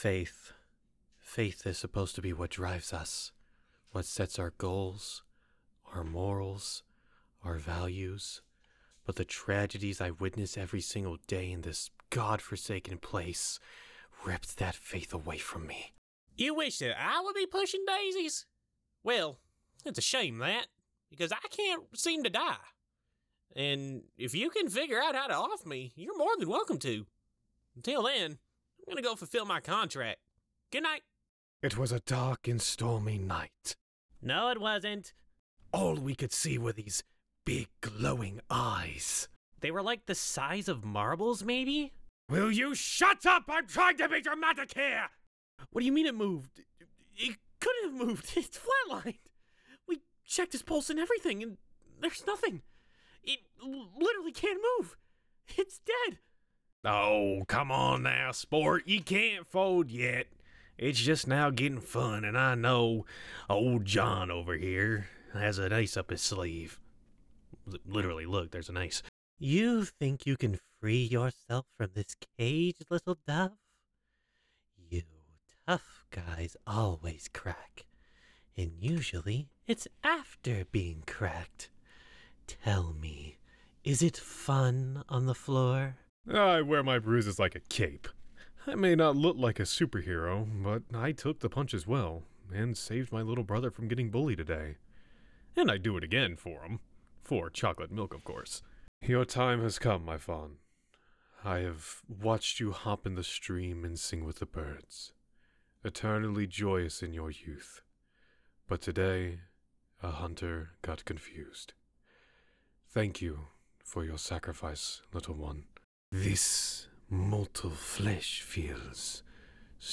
Professional Voiceover Artists, Actors & Talents Online
Teenager (13-17) | Yng Adult (18-29)